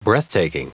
Prononciation du mot breathtaking en anglais (fichier audio)
Prononciation du mot : breathtaking